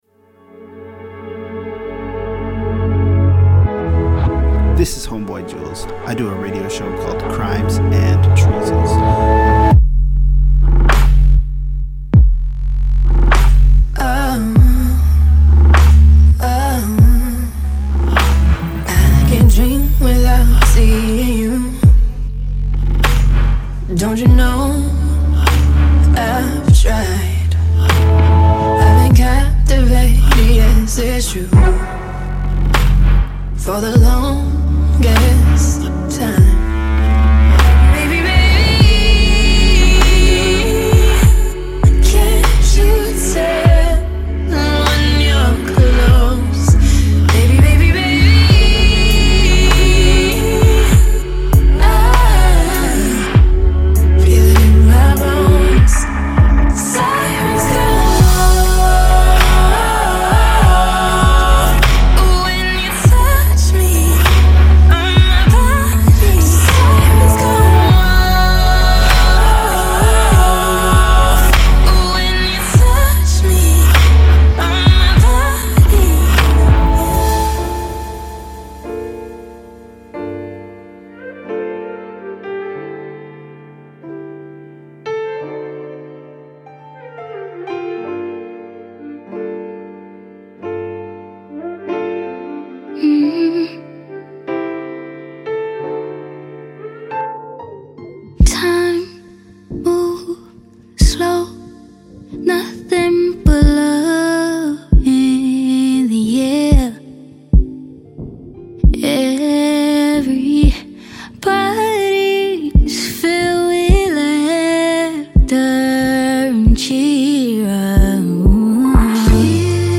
Female Hip Hop and RNB